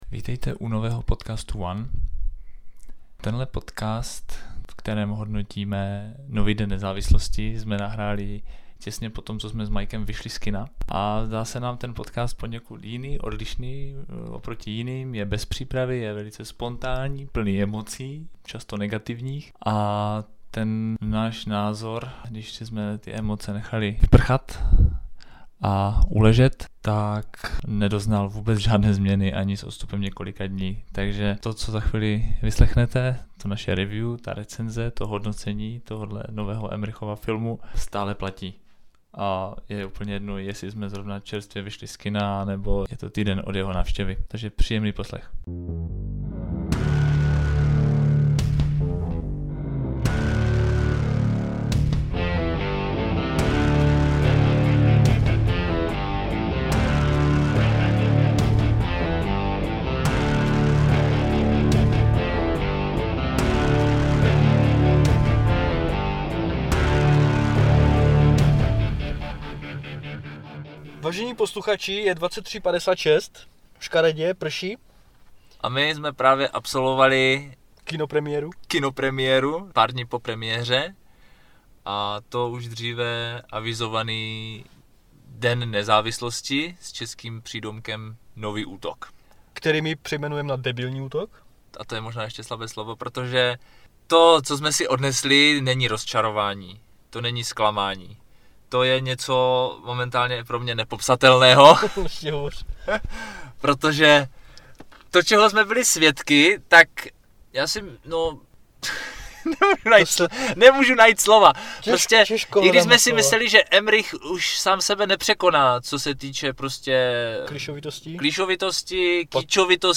Z kina jsme vyběhli plní emocí (byli jsme pořádně naštvaní) a rozhodli se si někde sednou a nahrát svůj názor hned za tepla. Bylo kolem půlnoci a my to ze sebe vysypali jako nikdy předtím.